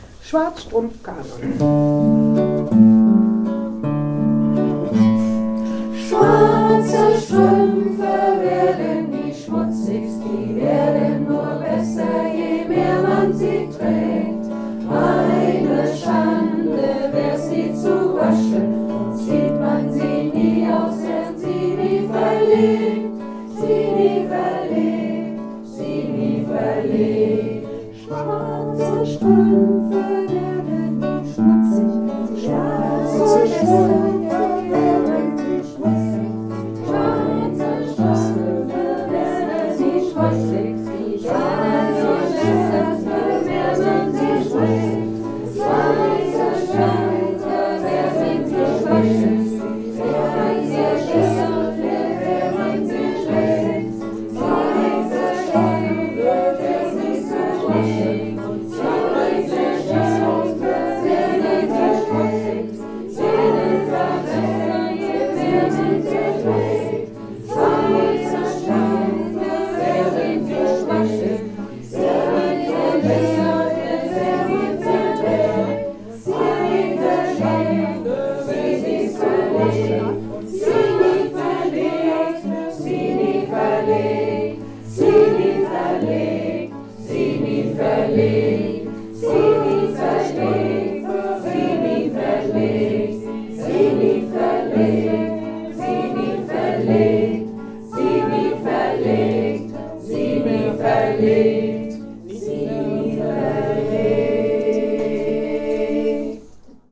2. Liederwerkstatt Juli 2024
Mehrstimmig, Kanons mit exzelenter Gitarrenbegleitung.
Alle Lieder und Stimmen werden aufgenommen und zur Verfügung gestellt.